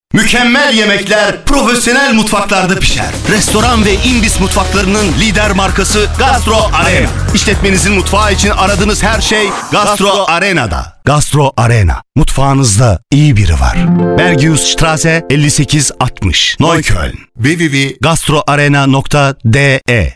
Radio Werbung